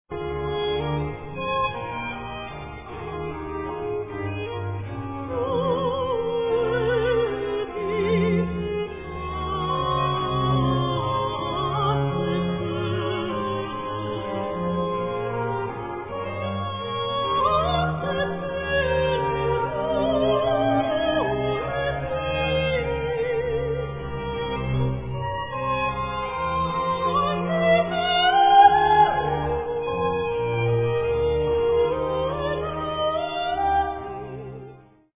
Three virtuosic soprano solo cantatas
Performed on period instruments.
14. Aria: